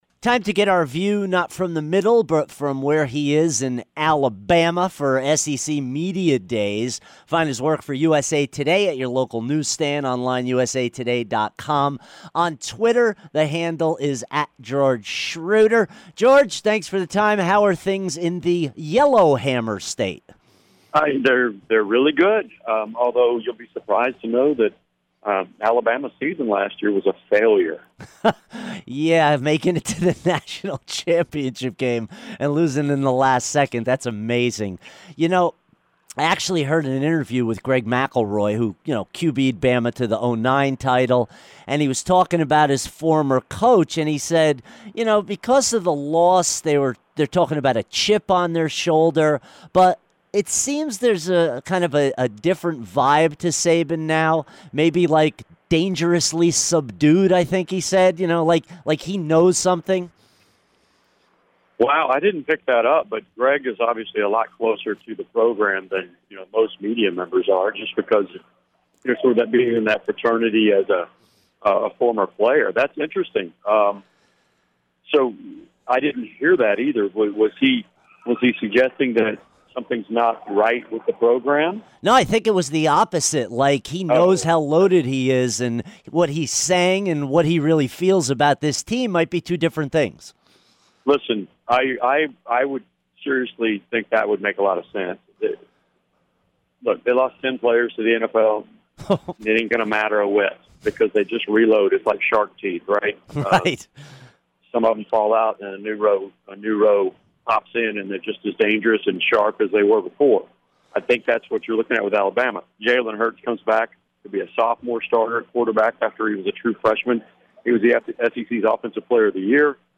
live at SEC Media Day